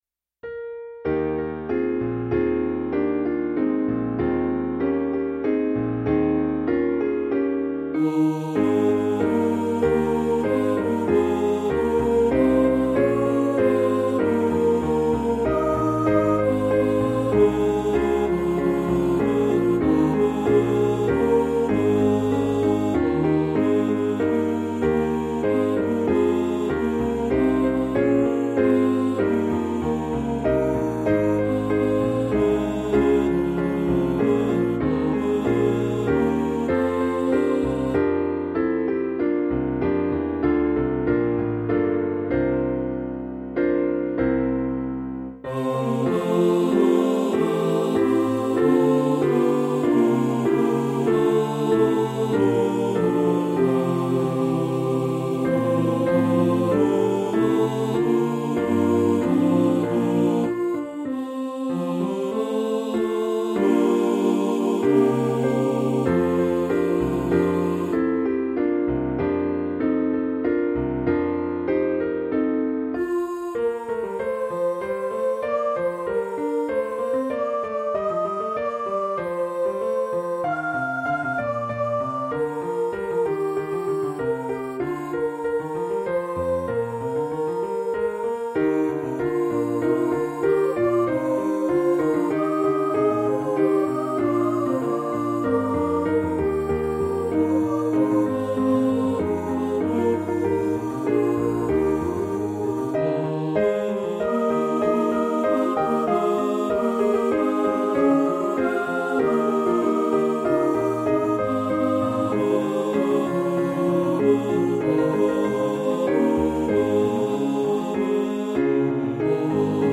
CLASSICAL STYLE
Piano Accompaniment with simulated choir.